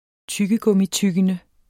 Udtale [ -ˌtygənə ]